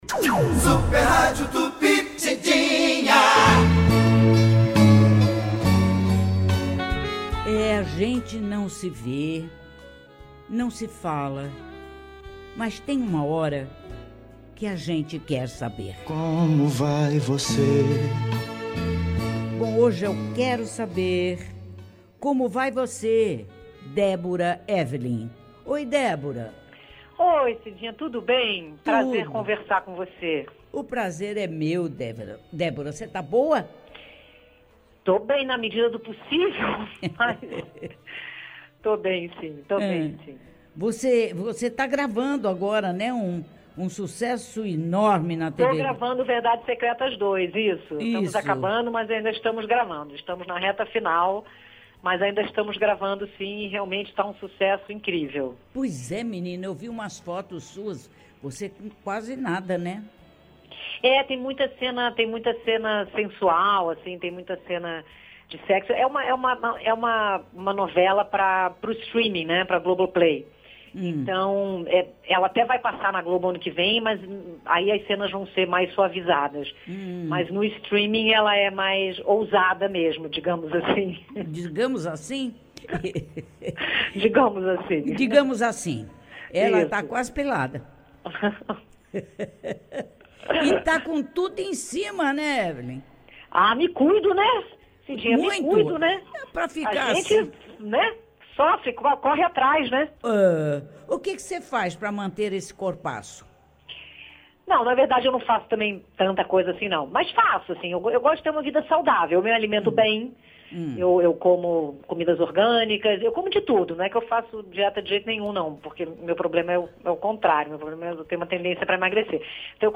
Déborah Evelyn, está no elenco e falou sobre a novela de Walcyr Carrasco durante entrevista ao programa Cidinha Livre, da Super Rádio Tupi.